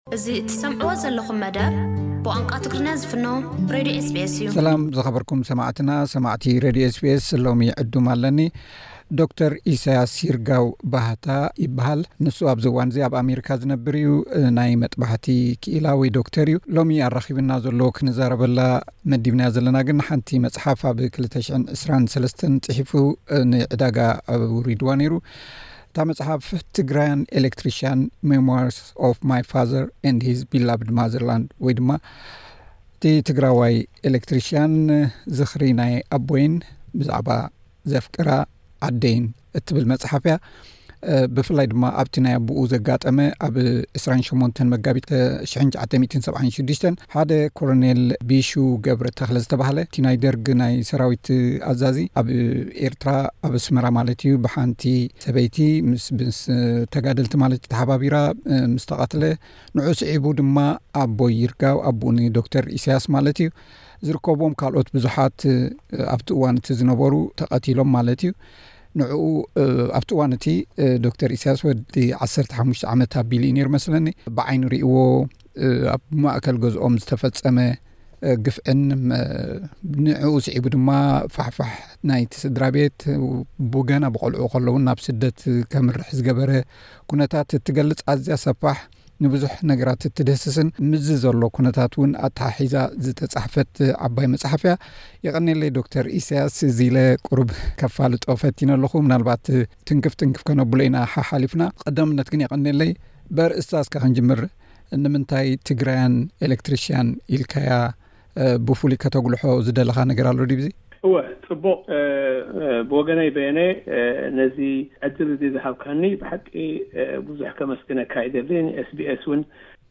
ዕላል ምስ ጸሓፊ